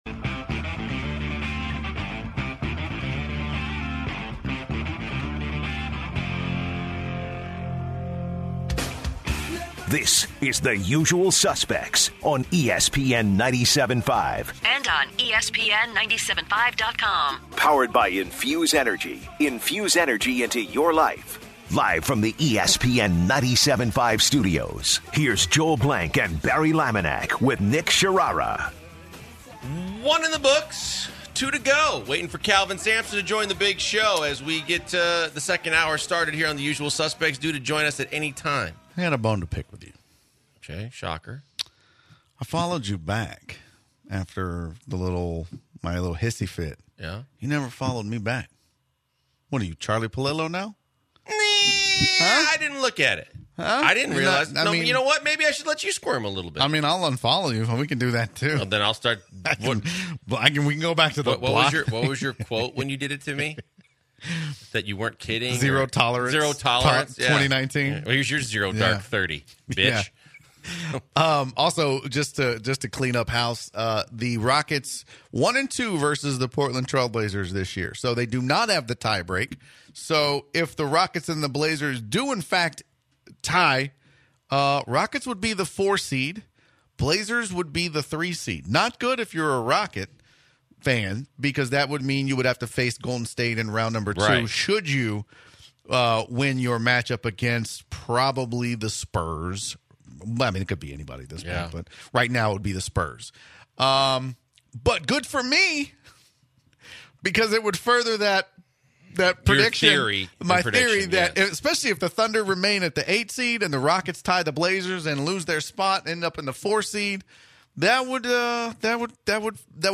Coach Kelvin Sampson joins the Suspects to talk about his teams phenomenal season.